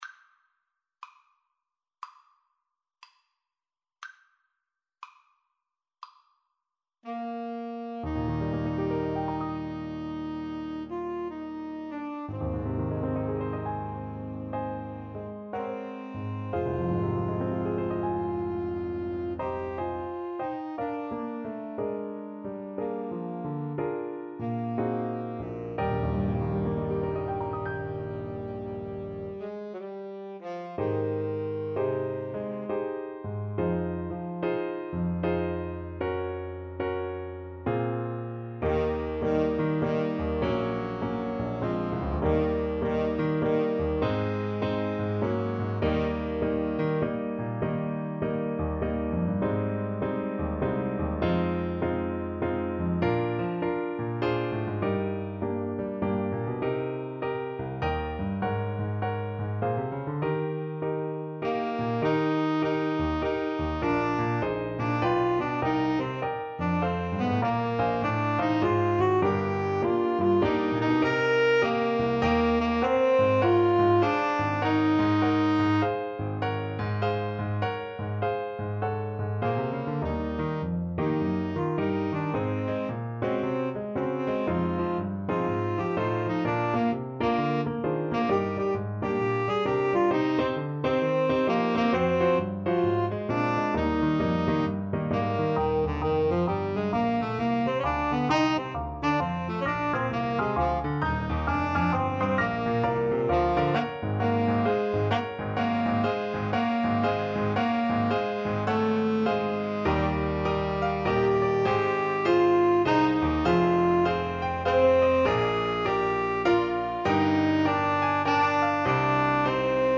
Alto SaxophoneTenor Saxophone
Adagio (swung throughout) =c.60
Jazz (View more Jazz Alto-Tenor-Sax Duet Music)